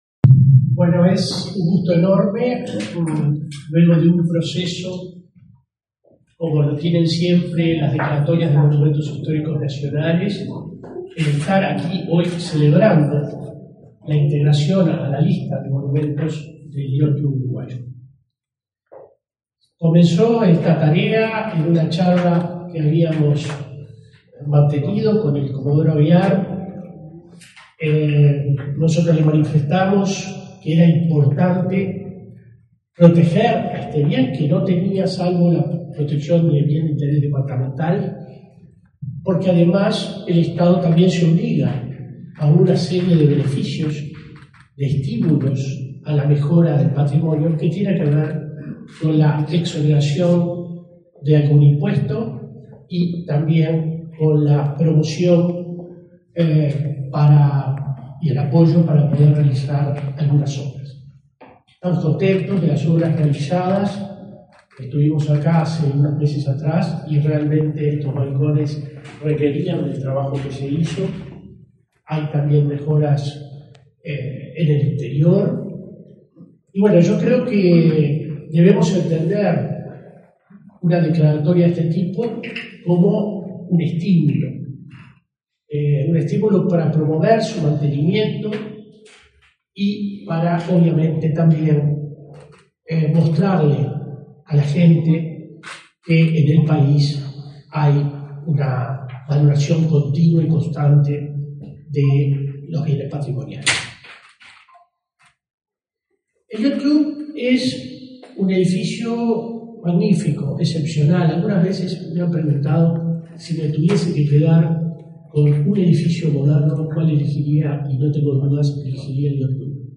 Palabras del director general de la Comisión de Patrimonio, William Rey